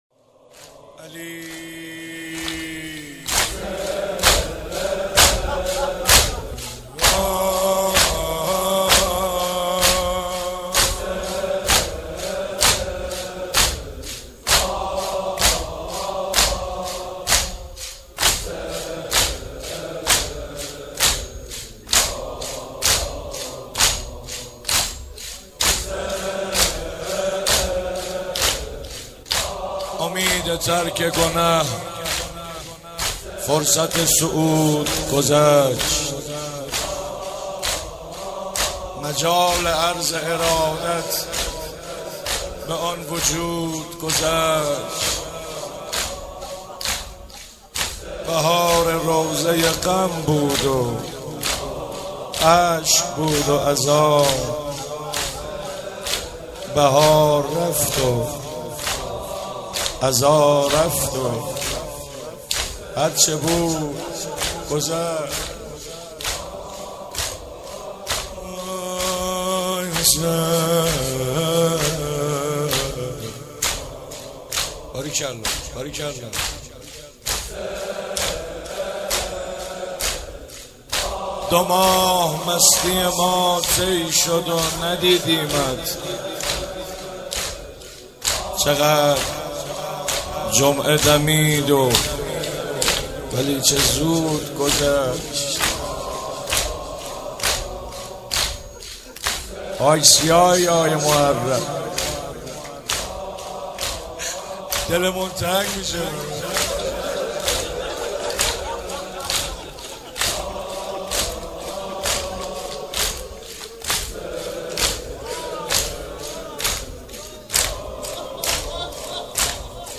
مناسبت : شهادت امام رضا علیه‌السلام
قالب : زمینه